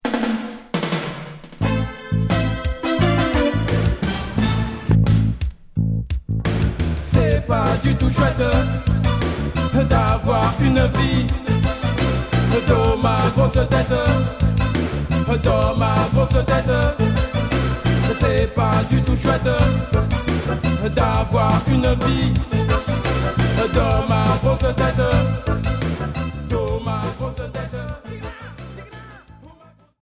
Reggae rub a dub